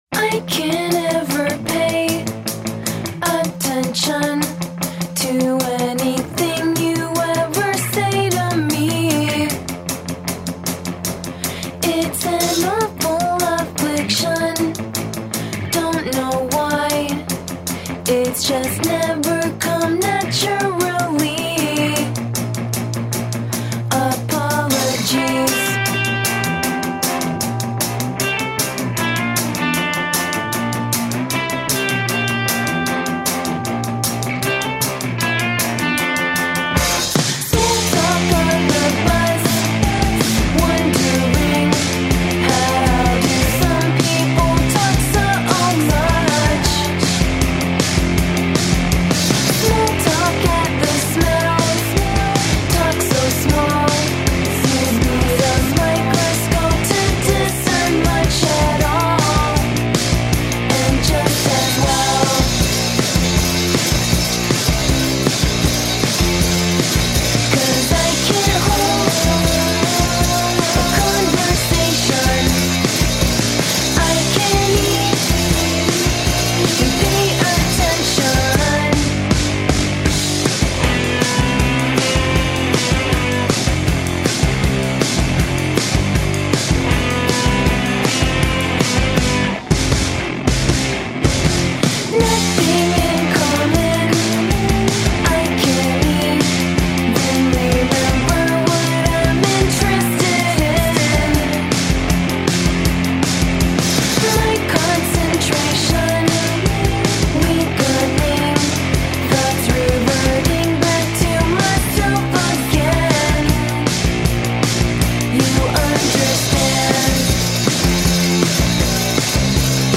The full band heard here